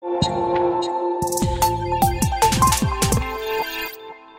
Power on.mp3